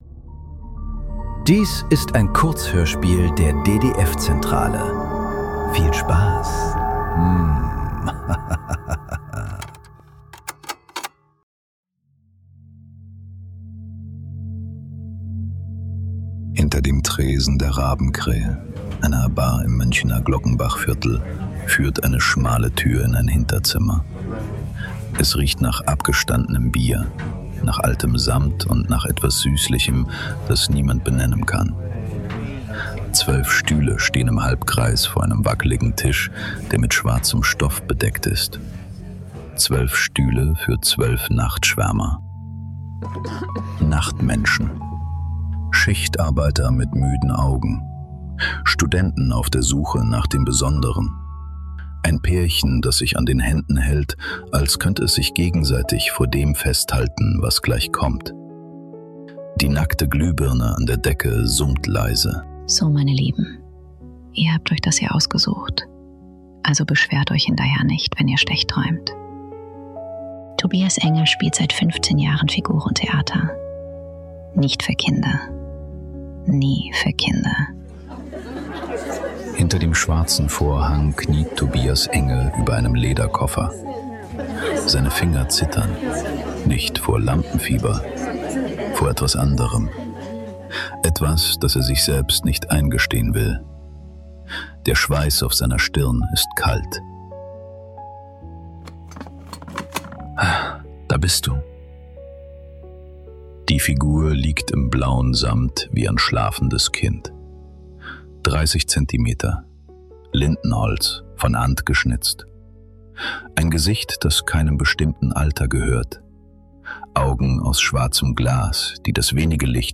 Ohne Fäden ~ Nachklang. Kurzhörspiele. Leise.